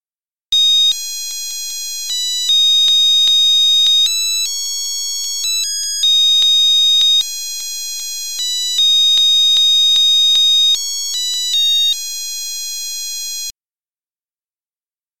einem fröhlichen Seemannslied